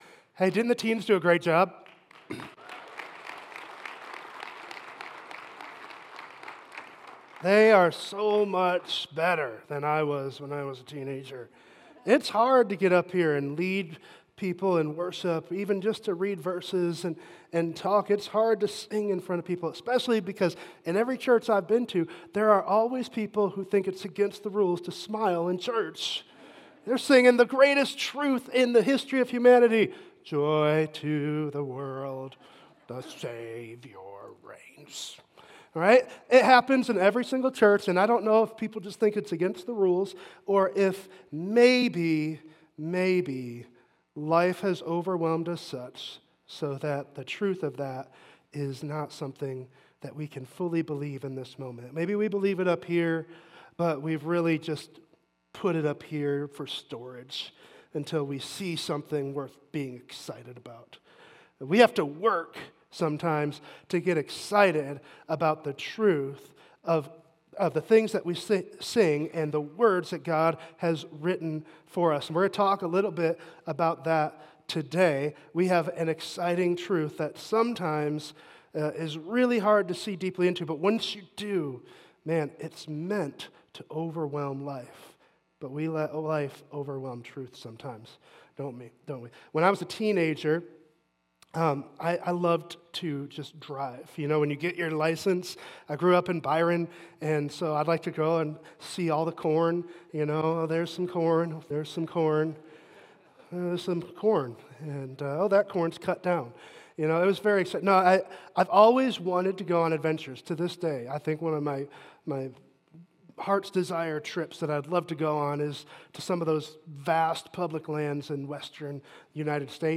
Sermons Archive - Mayfair Bible Church